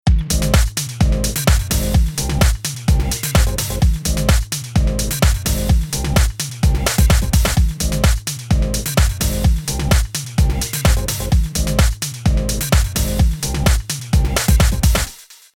Basic loop treated with SSL buss compression